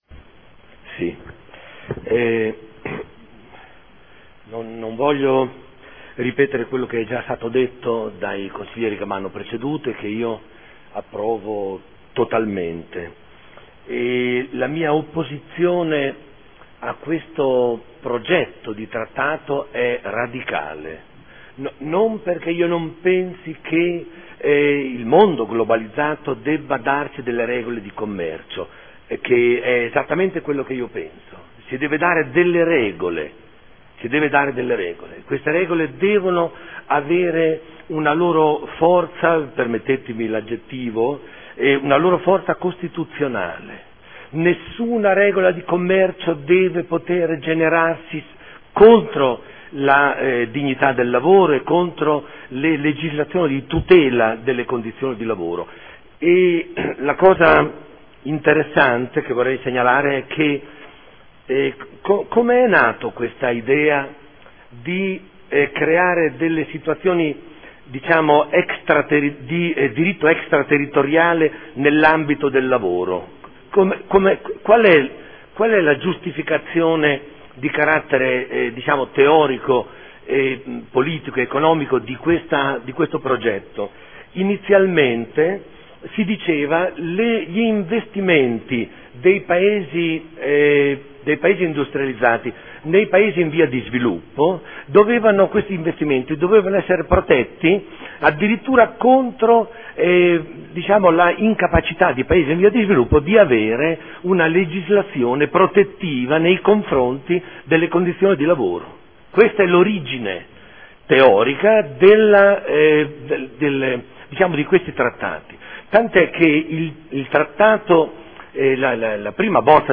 Domenico Campana — Sito Audio Consiglio Comunale